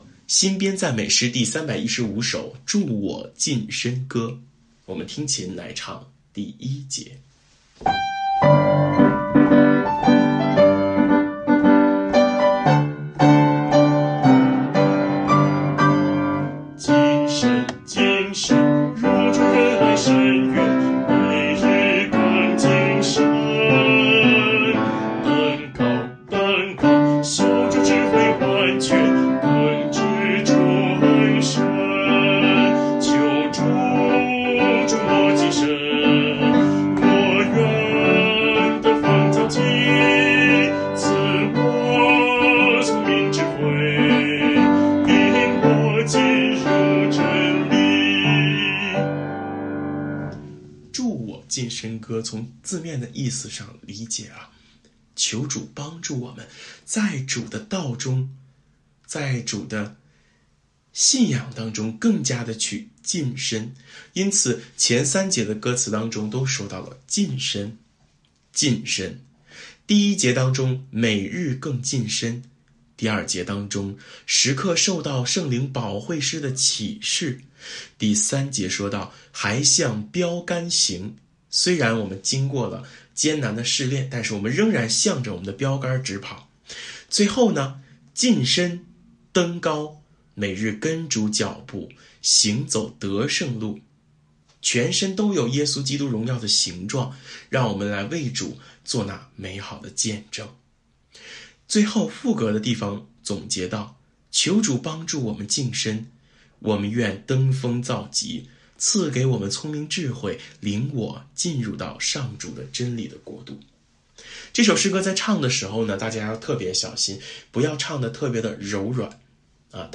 【颂唱练习】